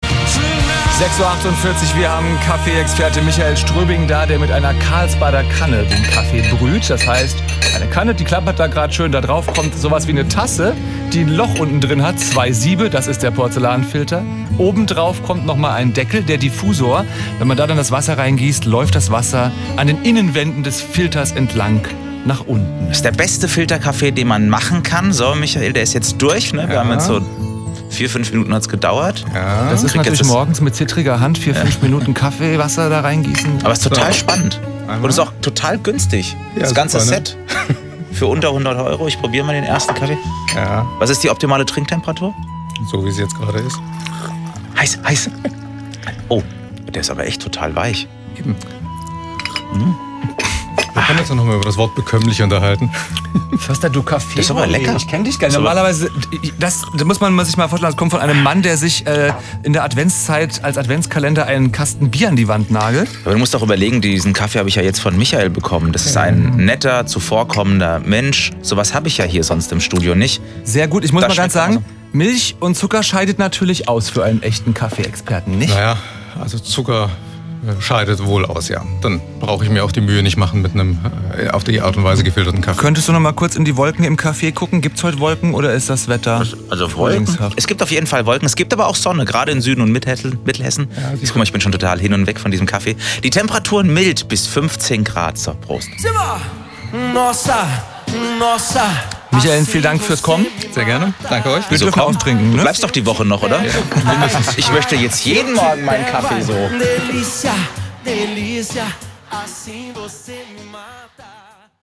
Wir sind heute morgen mit zwei unserer Karlsbader Kannen ins Funkhaus umgezogen und haben in hr3 “Pop&Weck” ein wenig Kaffeebrühen zelebriert.
Hier die Mitschnitte: